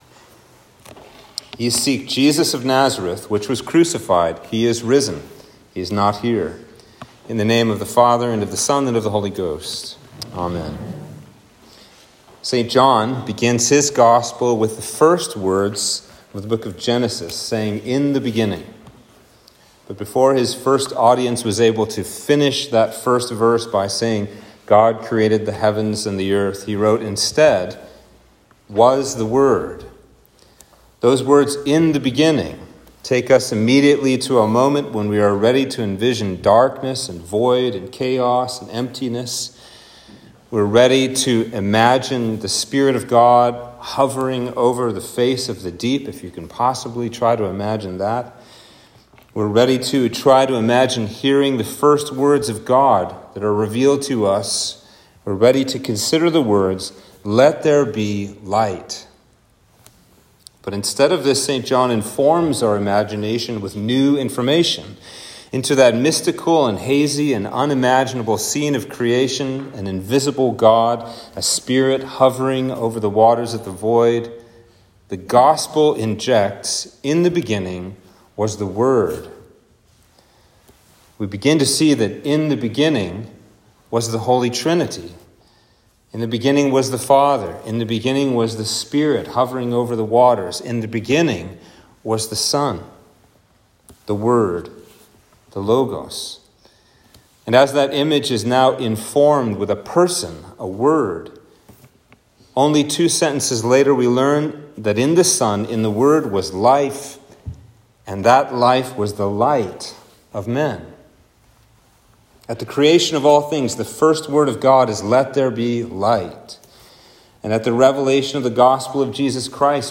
Sermon for Easter Vigil